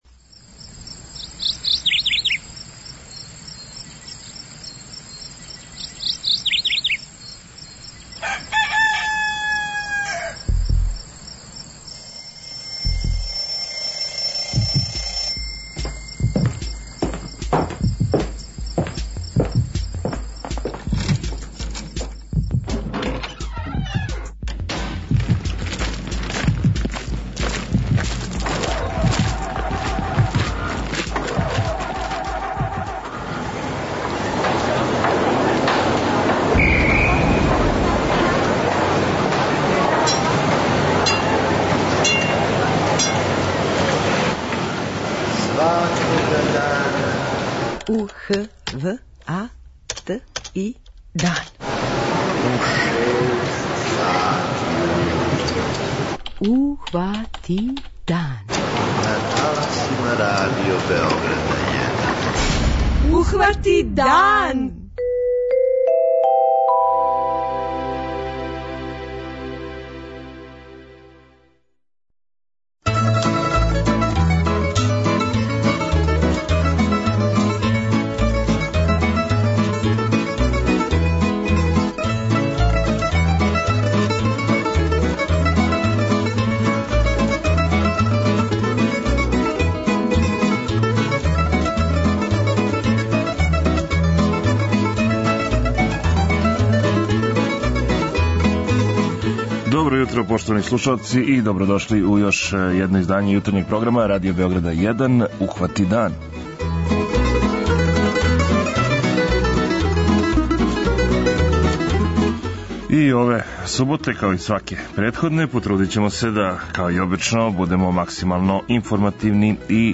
О предсојећој зимској туристичкој сезони у нашој земљи јутрос у хотелу „Стара планина", на Старој Планини